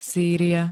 Zvukové nahrávky niektorých slov
xjhy-syria.spx